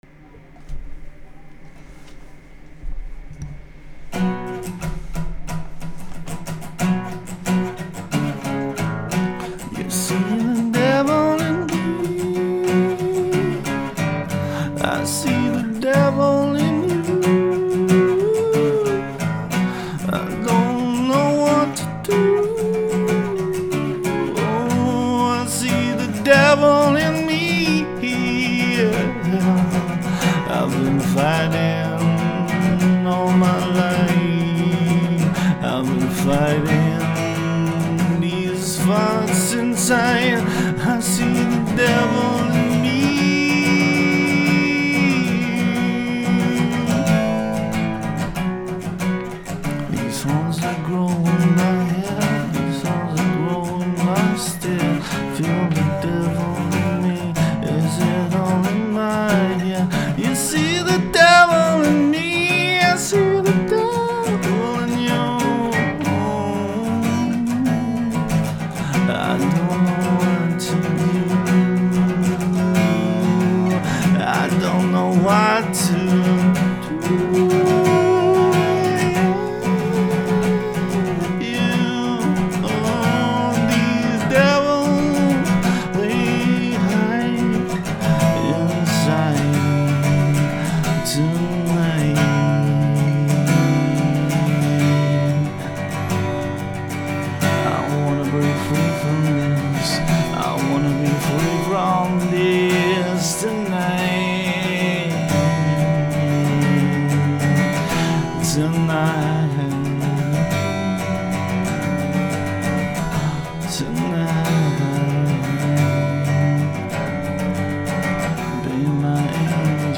guitar
acoustic uk us folk punk metal